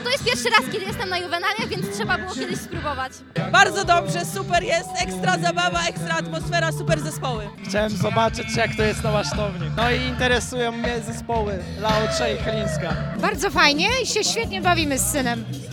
O swoich wrażeniach opowiadają uczestnicy imprezy.